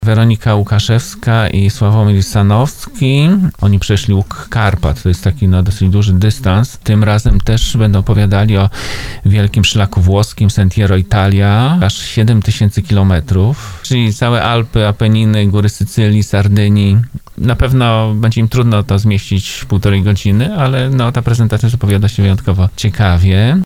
mówił na antenie RDN Małopolska